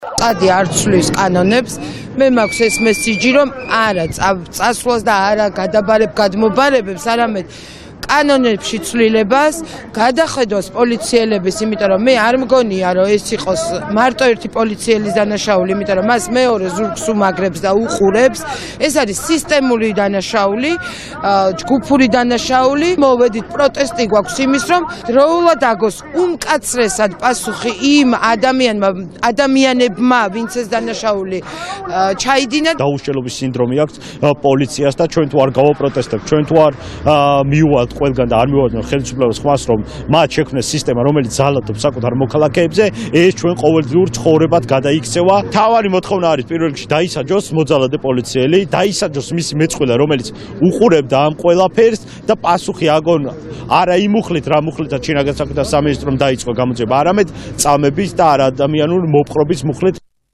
აქციის მონაწილეები
aqciis-monawileebi.mp3